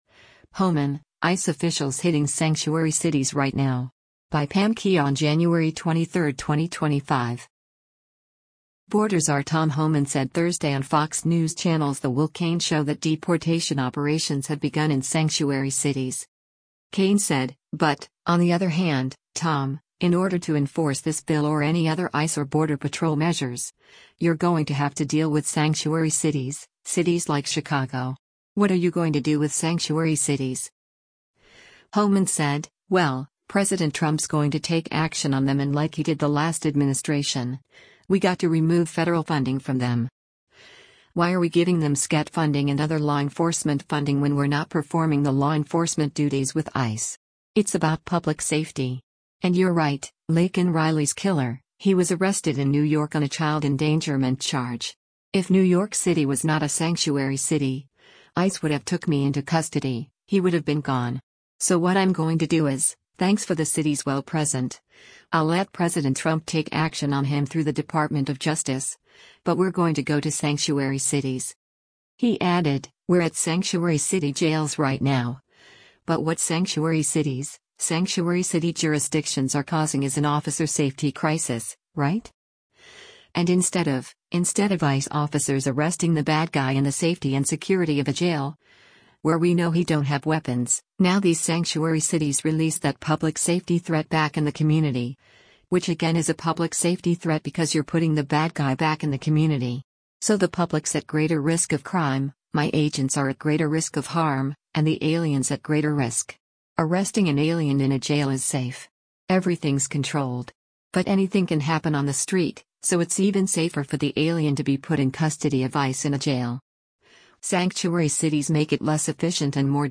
Border czar Tom Homan said Thursday on Fox News Channel’s “The Will Cain Show” that deportation operations have begun in sanctuary cities.